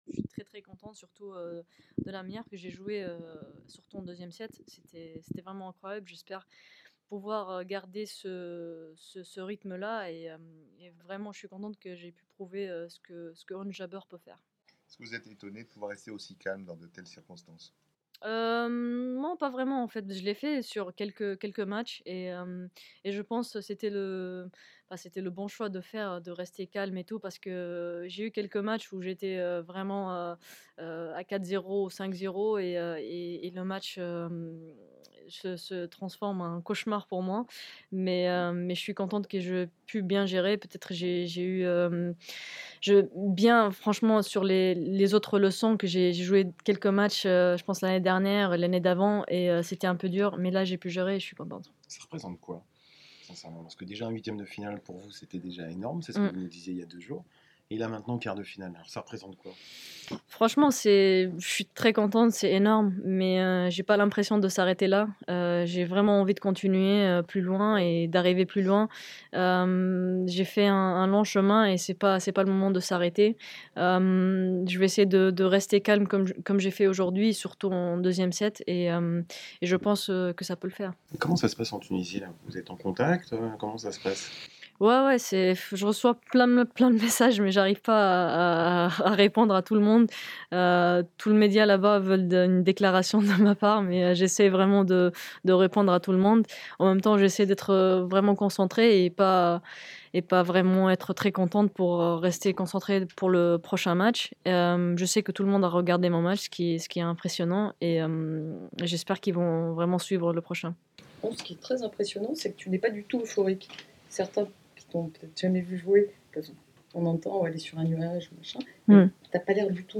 Le rêve continue pour Ons Jabeur, qui a disposé dimanche en deux sets de la Chinoise Wang Qiang. Avant d'affronter l'Américaine Sofia Kenin mardi en quart de finale, elle livre ses impressions en conférence de presse.